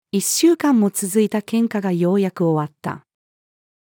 一週間も続いた喧嘩がようやく終わった。-female.mp3